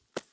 Dirt Foot Step 4.wav